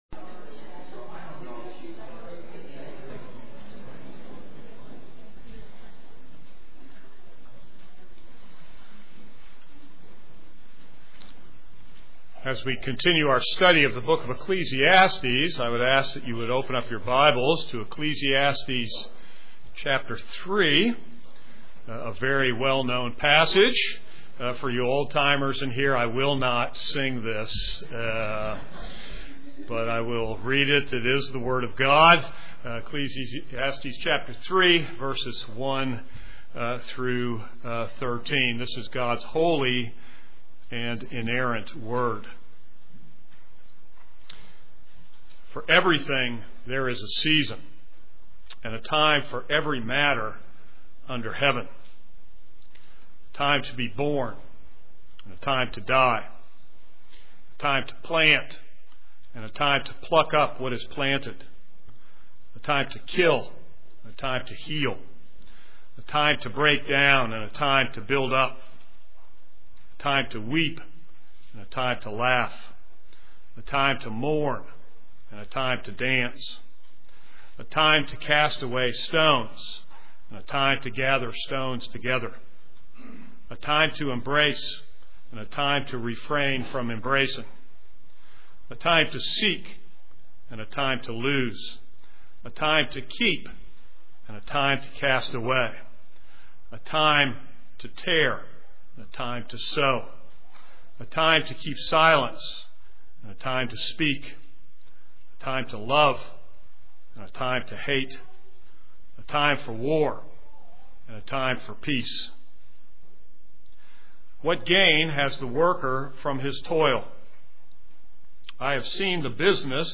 This is a sermon on Ecclesiastes 3:1-13.